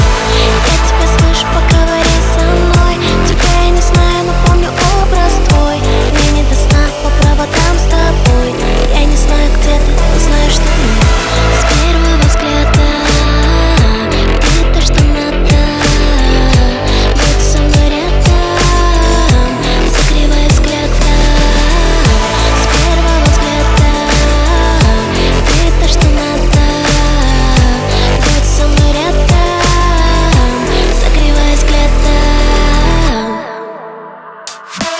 Жанр: Русская поп-музыка / Поп / Русские